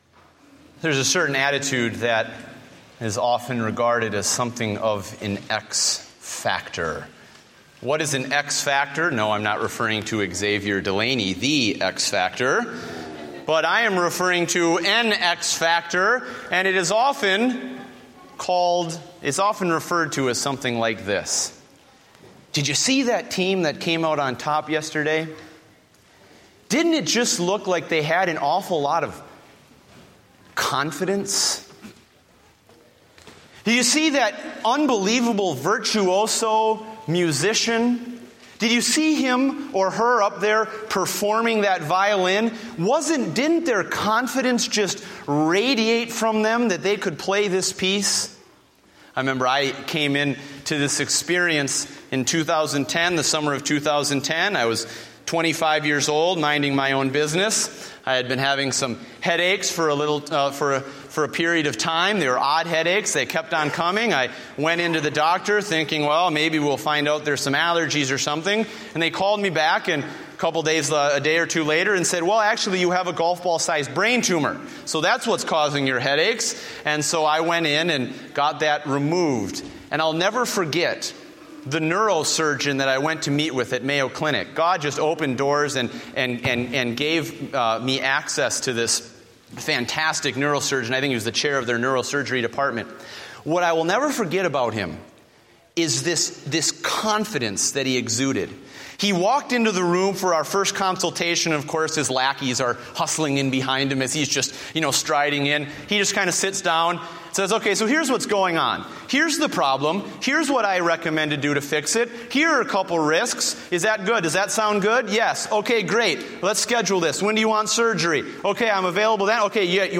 Date: October 26, 2014 (Morning Service)